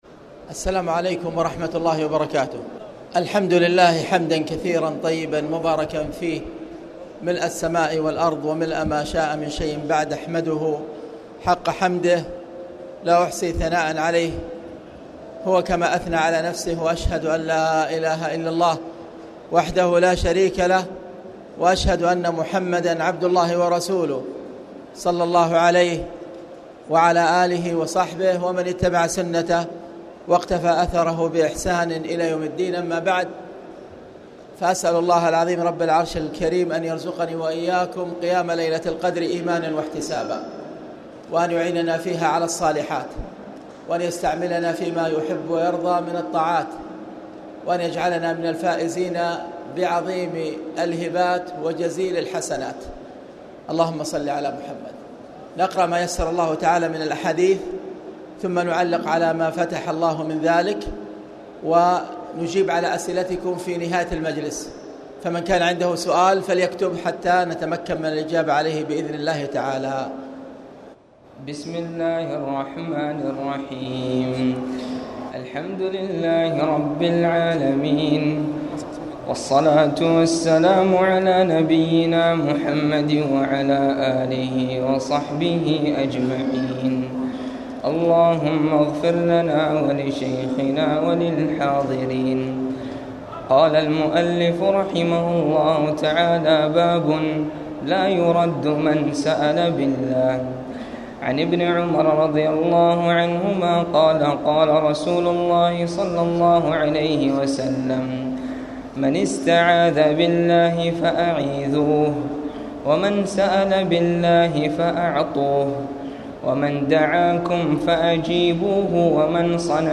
تاريخ النشر ٢١ رمضان ١٤٣٨ هـ المكان: المسجد الحرام الشيخ